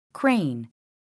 [krein] 듣기